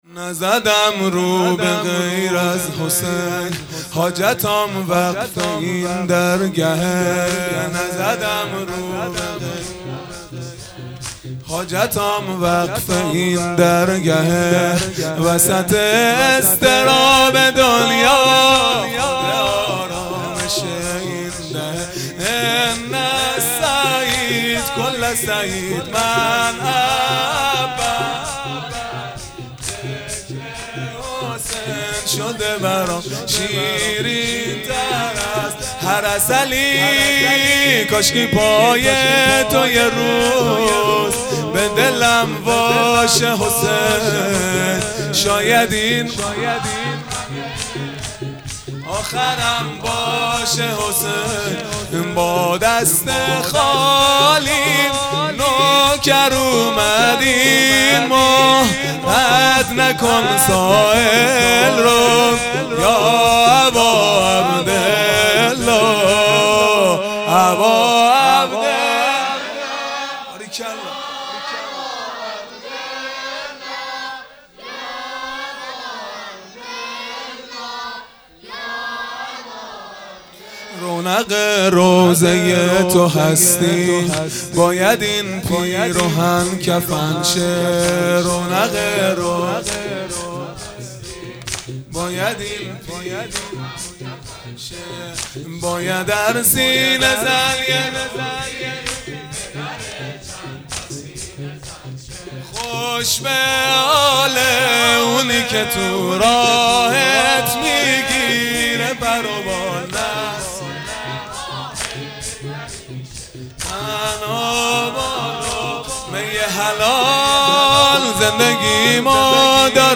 سرود پایانی | نزدم رو به غیر از حسین | دوشنبه ۲۵ مرداد ۱۴۰۰
دهه اول محرم الحرام ۱۴۴۳ | شب هشتم | دوشنبه ۲۵ مرداد ۱۴۰۰